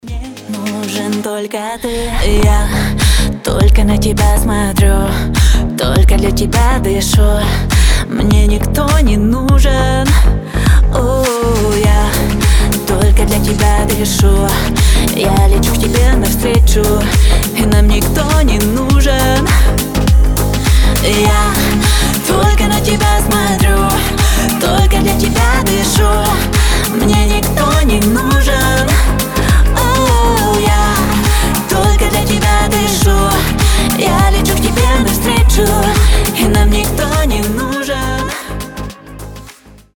• Качество: 320, Stereo
женский голос